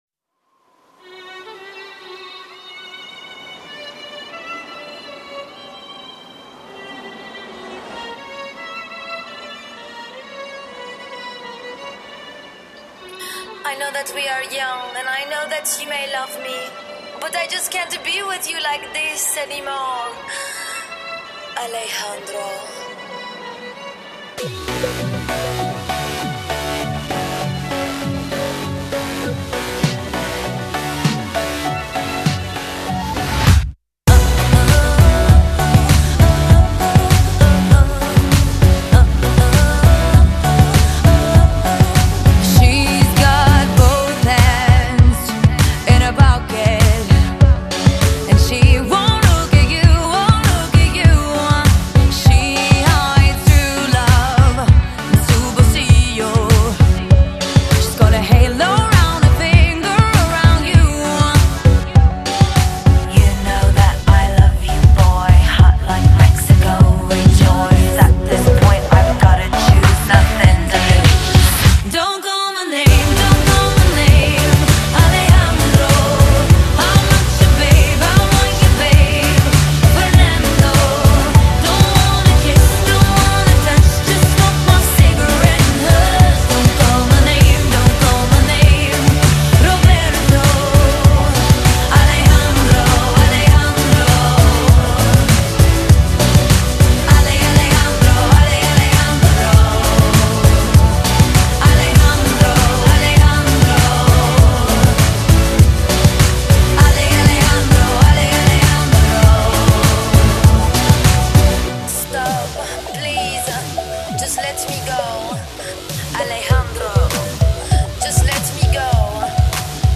зарубежная эстрада